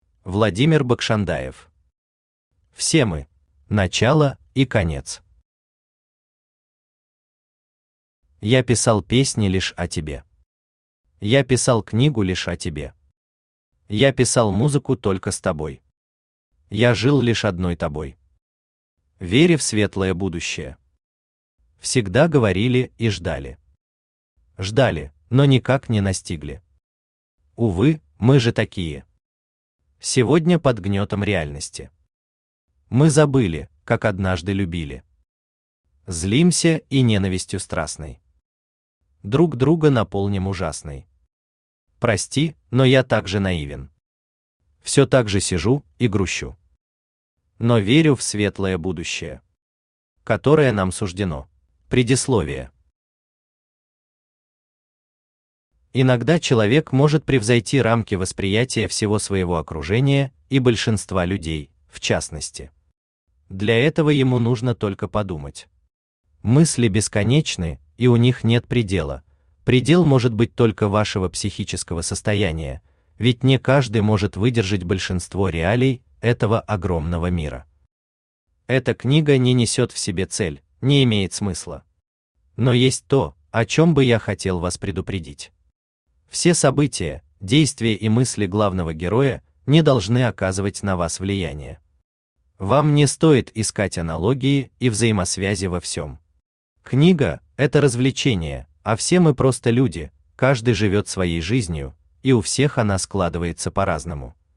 Аудиокнига Все мы | Библиотека аудиокниг
Aудиокнига Все мы Автор Владимир Бакшандаев Читает аудиокнигу Авточтец ЛитРес.